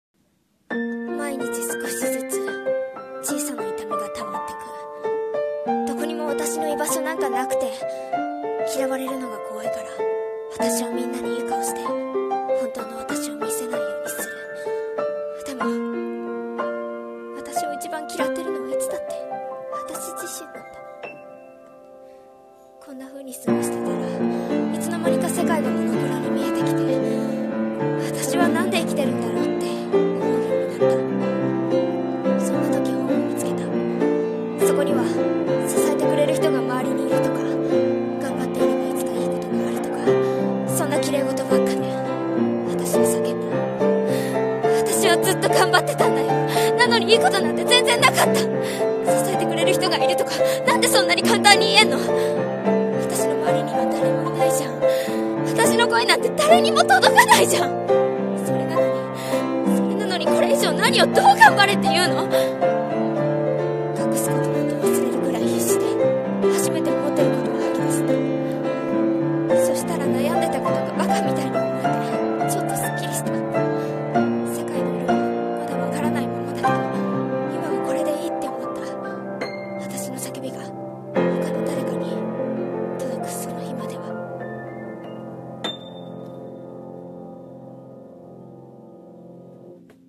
【1人声劇】
【朗読】